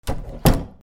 扉
/ K｜フォーリー(開閉) / K05 ｜ドア(扉)